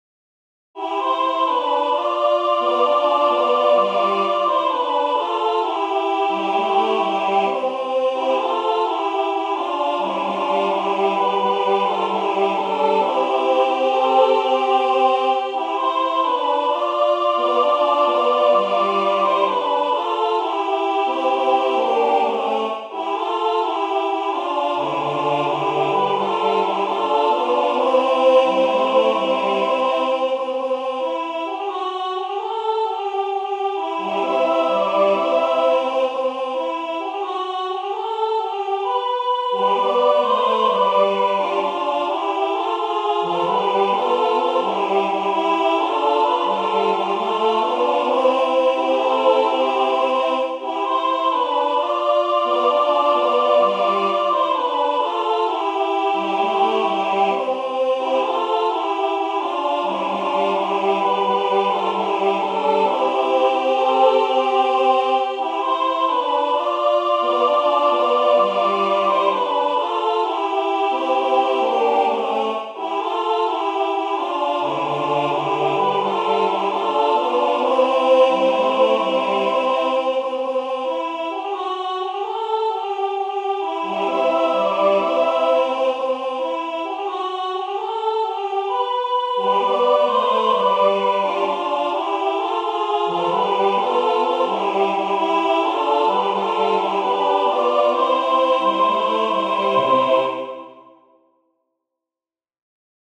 Formación:SAB
Género:Havanera